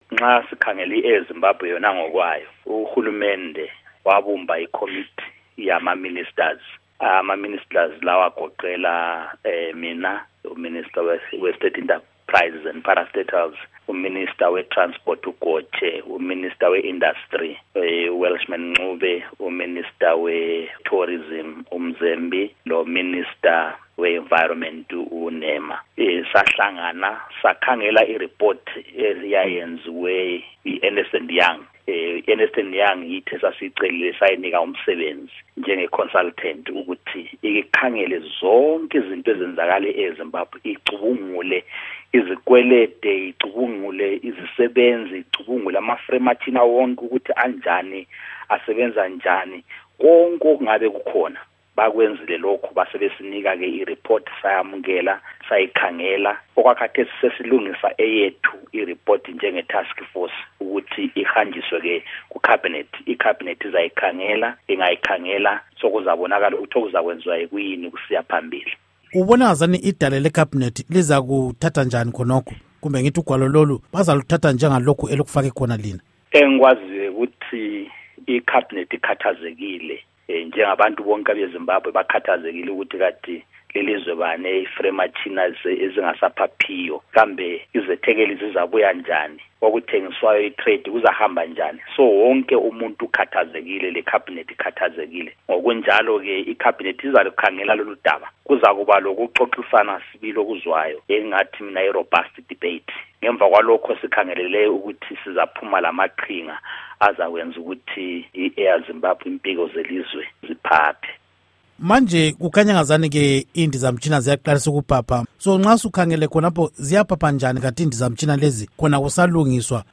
Ingxoxo Esiyenze LoMnu. Gorden Moyo